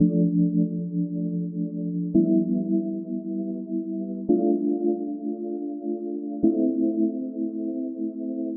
Tag: 112 bpm Acoustic Loops Piano Loops 1.44 MB wav Key : Unknown